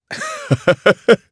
Chase-Vox_Happy3_jp.wav